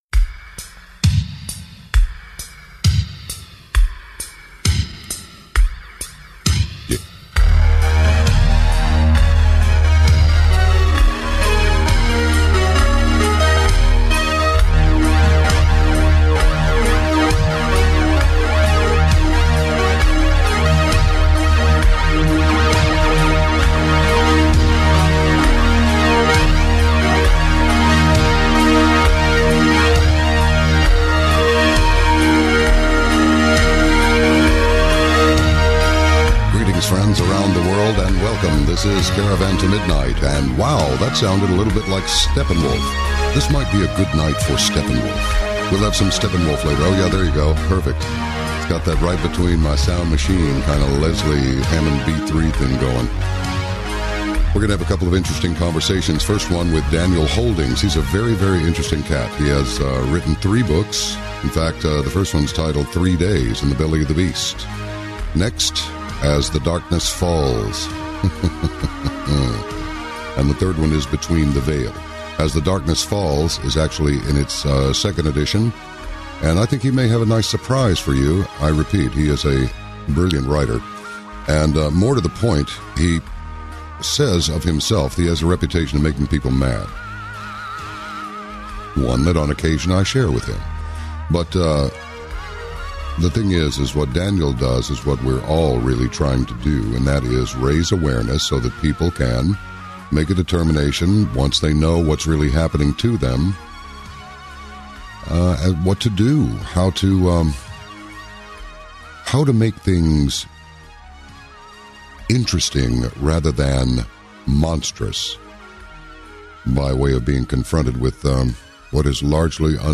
The men discuss everything from the upcoming election, financial collapse, nuclear war and more.
It is a discussion that you do not want to miss!